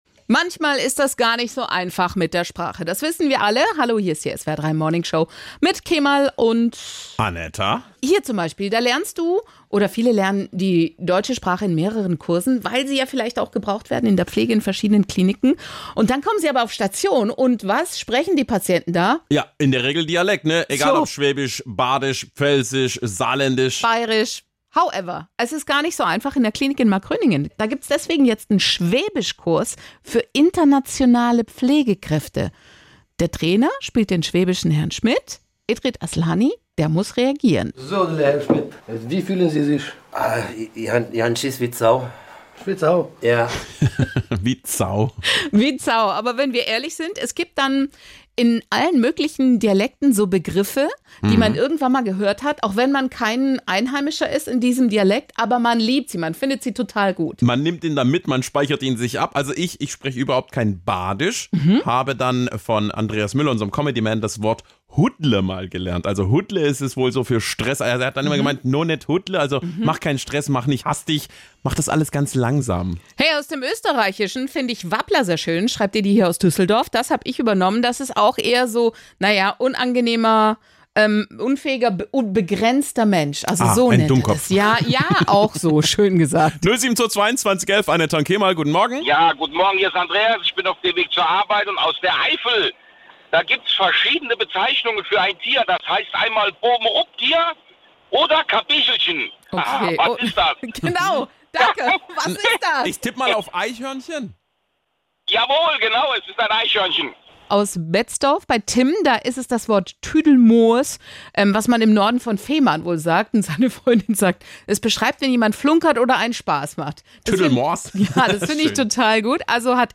Hier gibts das Best-of aus dem Radio: